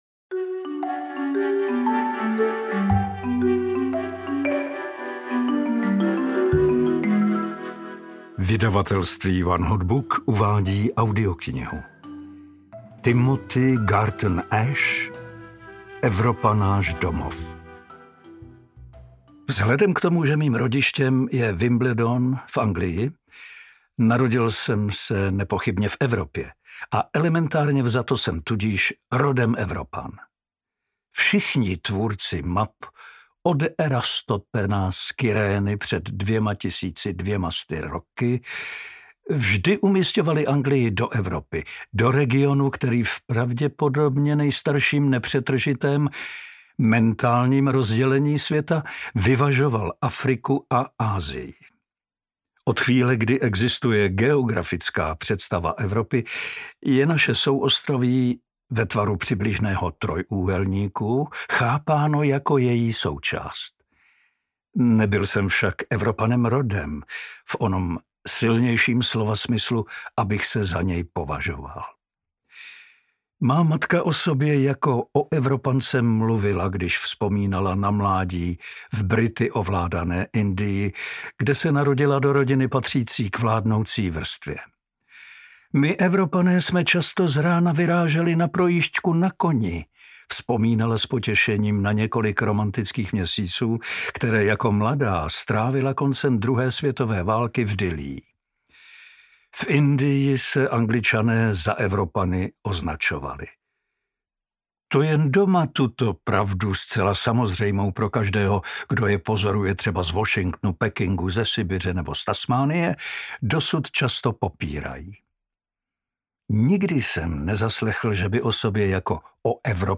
Evropa, náš domov: Od vylodění v Normandii po válku na Ukrajině audiokniha
Ukázka z knihy
evropa-nas-domov-od-vylodeni-v-normandii-po-valku-na-ukrajine-audiokniha